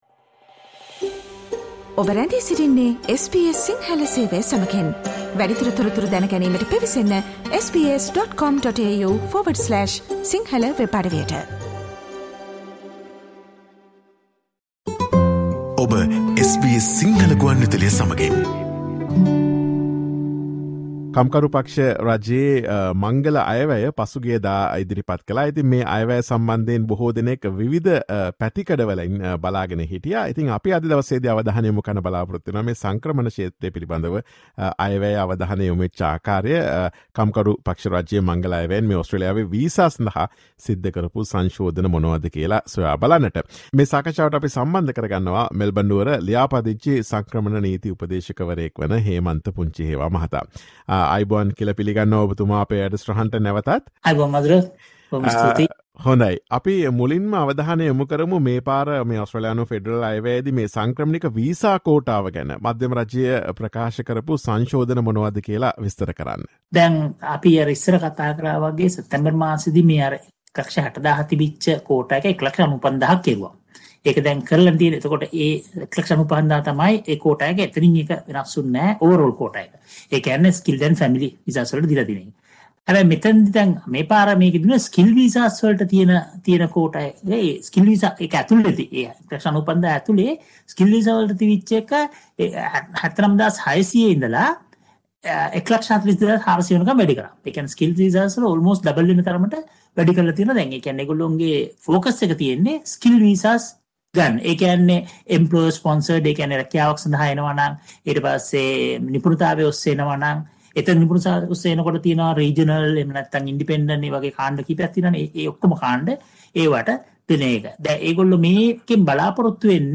Listen to SBS Sinhala Radio discussion of the migrant visa amendments announced in the latest Australian Federal Budget.